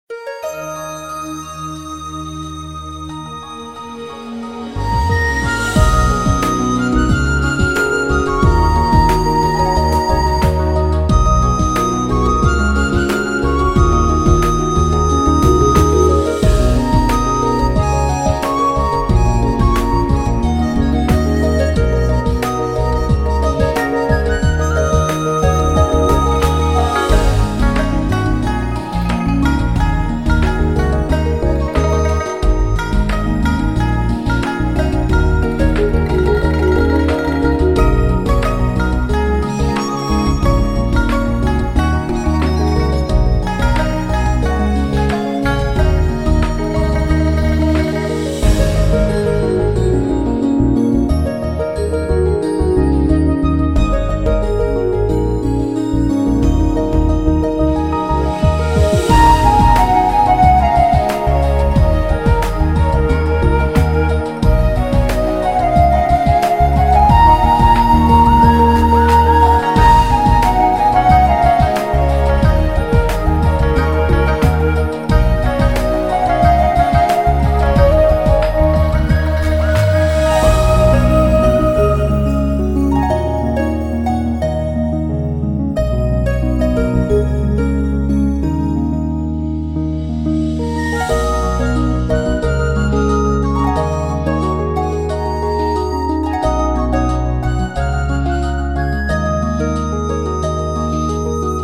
轻快飘逸曲风